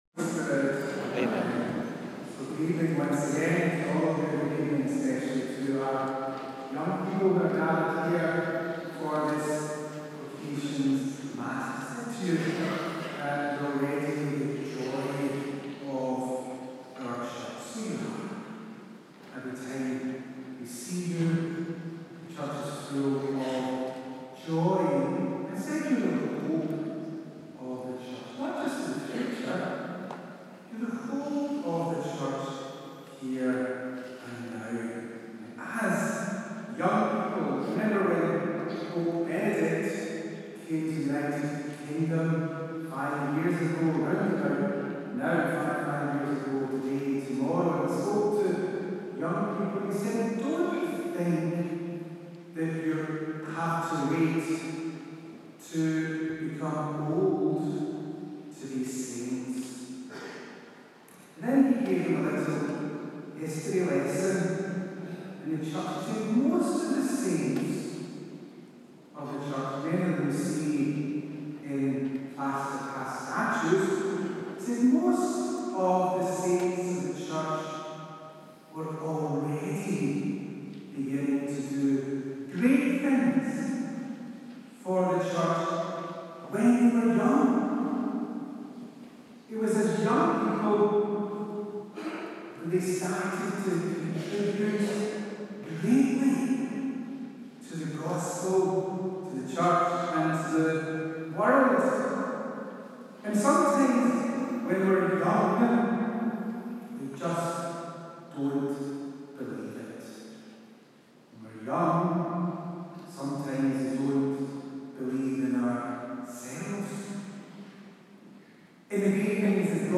bishop john on vocations Mass